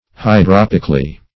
hydropically - definition of hydropically - synonyms, pronunciation, spelling from Free Dictionary Search Result for " hydropically" : The Collaborative International Dictionary of English v.0.48: Hydropically \Hy*drop"ic*al*ly\, adv.
hydropically.mp3